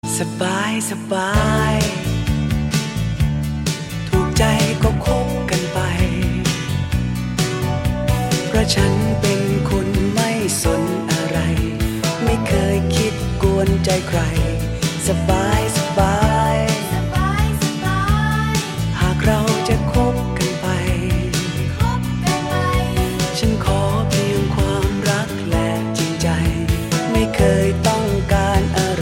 • Качество: 128, Stereo
Dance Pop
приятные
80-е